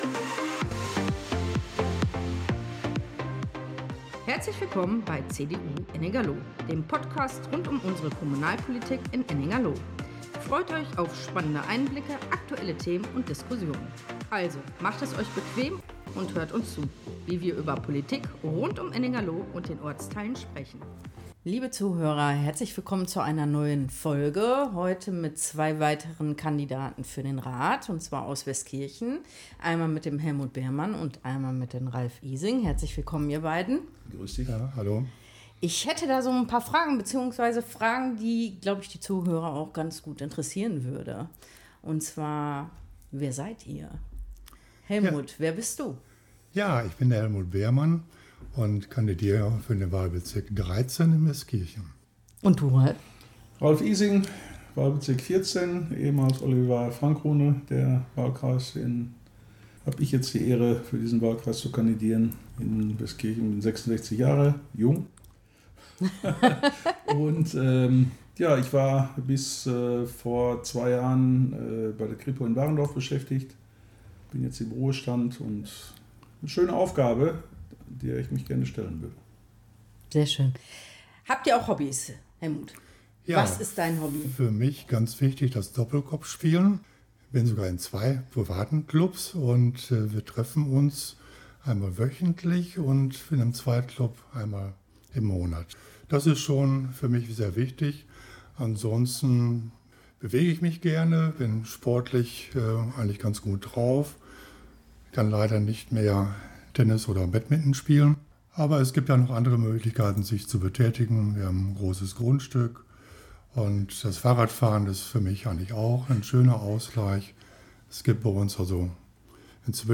Instrumental Lively-Instrumental Intro und Outro